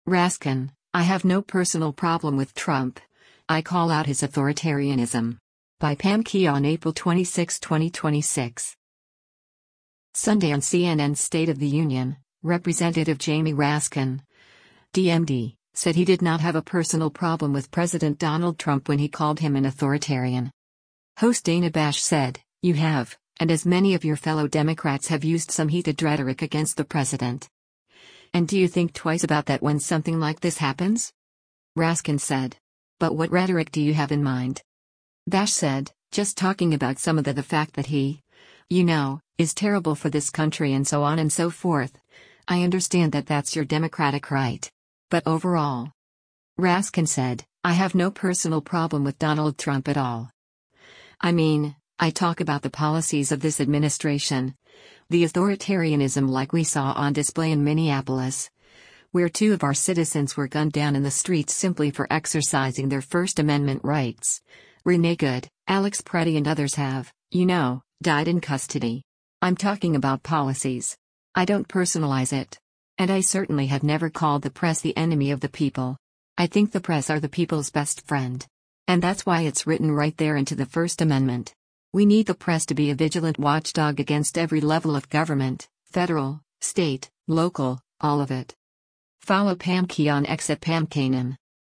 Sunday on CNN’s “State of the Union,” Rep. Jamie Raskin (D-MD) said he did not have a personal problem with President Donald Trump when he called him an “authoritarian.”